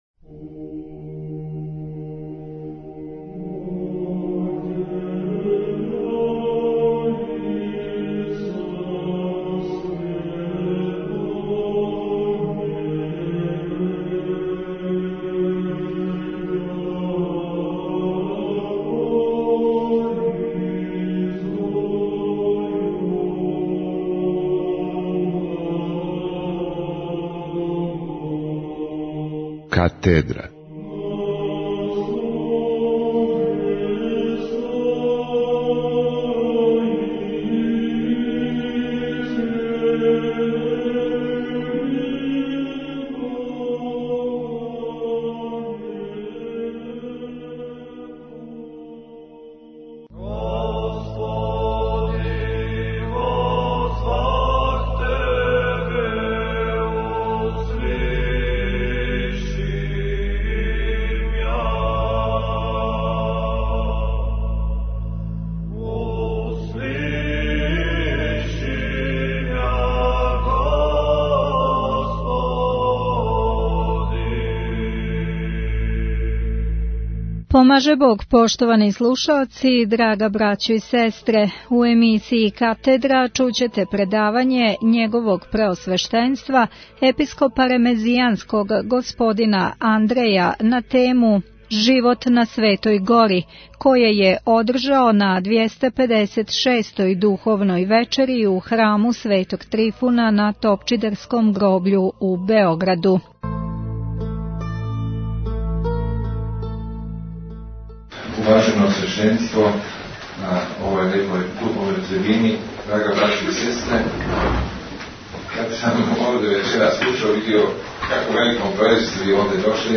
Преузмите аудио датотеку 431 преузимања 76 слушања Епископ Ремезијански Г. Андреј: Живот на Светој Гори Tagged: Катедра 65:54 минута (11.32 МБ) Предавање Његовог Преосвештенства Епископа Ремезијанског Г. Андреја на тему "Живот на Светој Гори" које је одржао на 256. вечери духовних разговора које организује Храм Светог Трифуна са Топчидерског гробља у Београду. Захваљујемо Радију "Слово љубве" на тонском запису овог предавања.